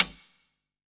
bouncehard1.wav